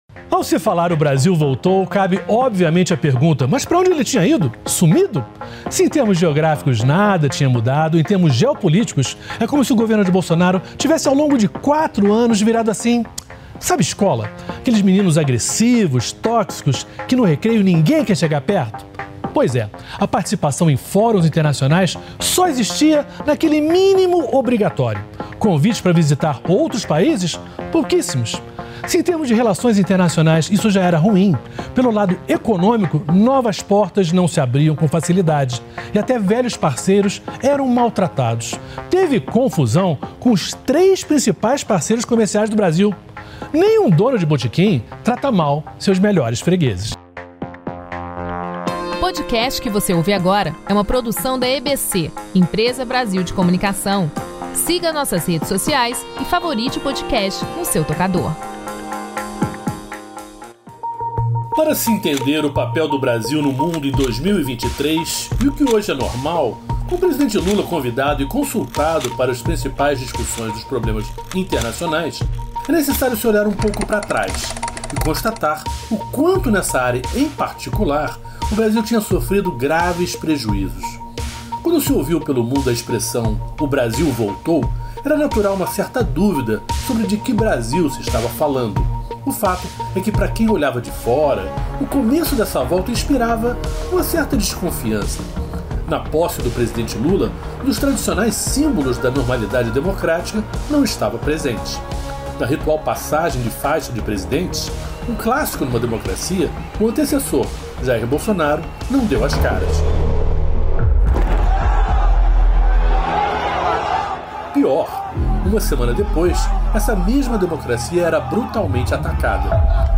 Marcos Uchôa conversa com líderes de países amazônicos sobre o papel de todos e de cada um para manter a floresta de pé e atrair o mundo para investir e ouvir a voz da Amazônia.